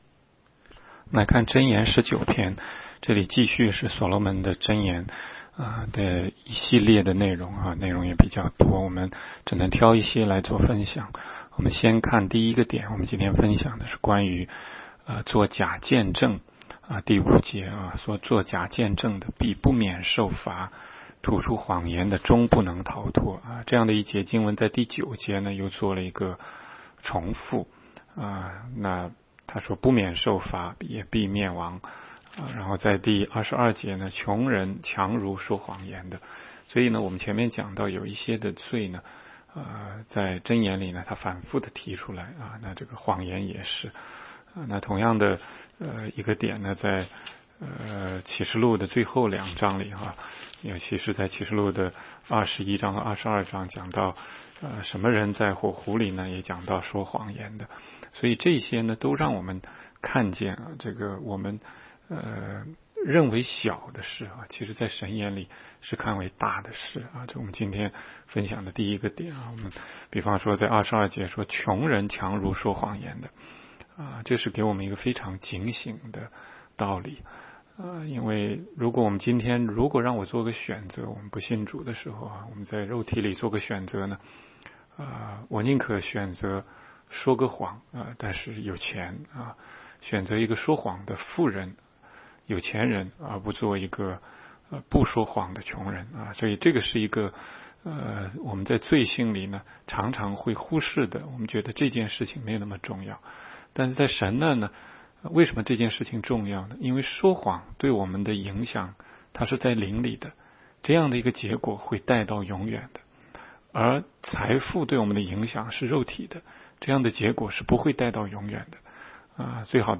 16街讲道录音 - 每日读经 -《 箴言》19章